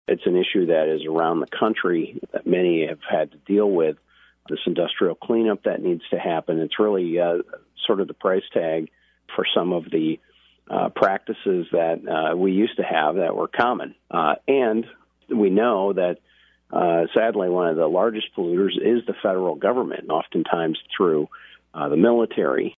“It’s an issue around the country that many have had to deal with,” he said on “WHTC Morning News” during his weekly appearance on Thursday.